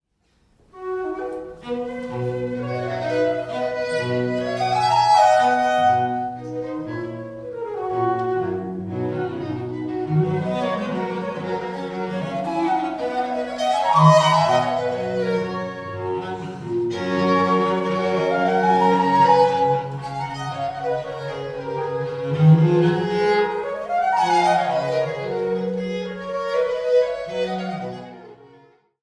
mp3Sirmen, Maddalena Laura, Trio Sonata in C Major, P.L.2.2 for two violins and continuo,
Vivace